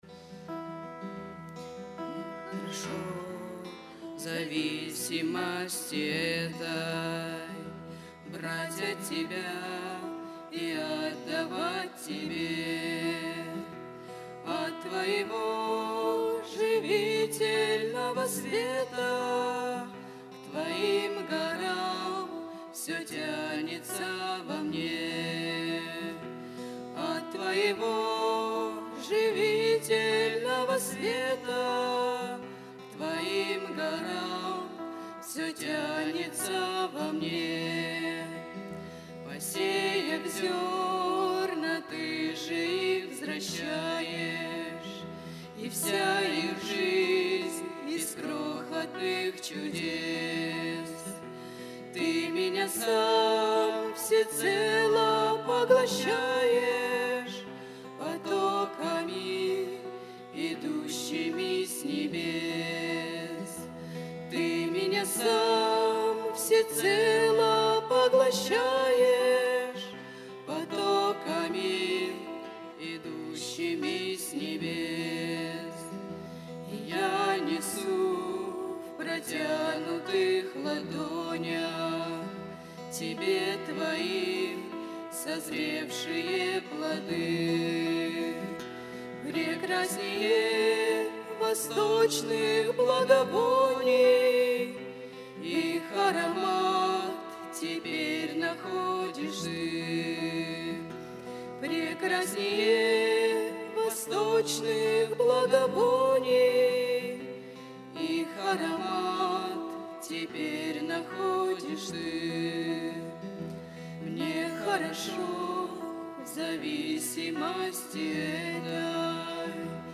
Богослужение 25.08.2024
Пение